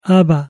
(Aba) - Means Father!
aba.mp3